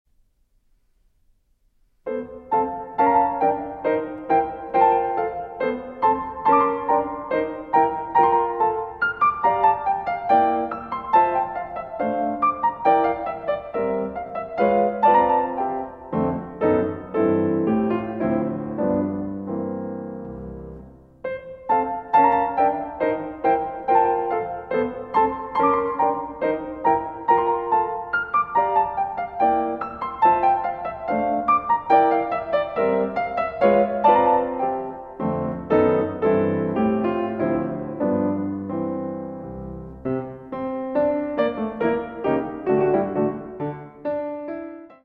Tempo di gavotto del’ XVII. secolo